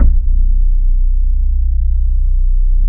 BASS 7    -R.wav